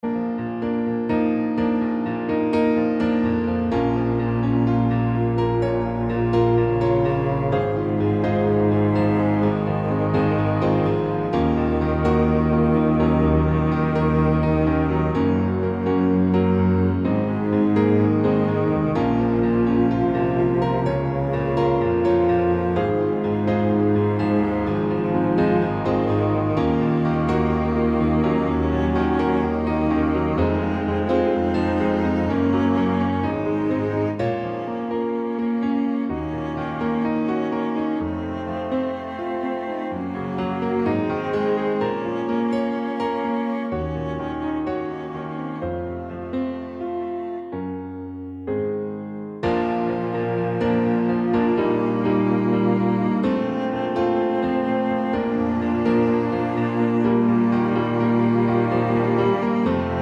Original Male Key No End Drums